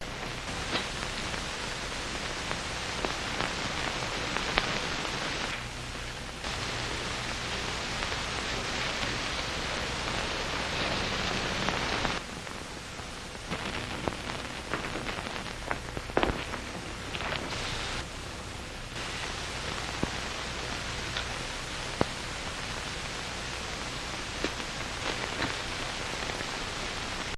Original Vinyl Static, Rustling
background crackle hiss lofi movies noise old original sound effect free sound royalty free Movies & TV